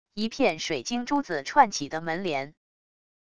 一片水晶珠子串起的门帘wav音频